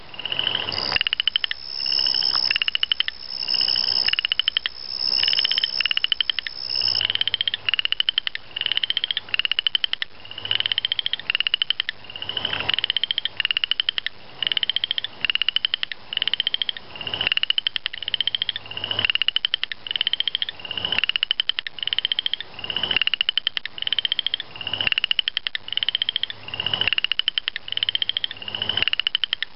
Here's a couple of Pseudacris nigrita saying a hearty farewell to Winter!
AprilChorusFrogs.mp3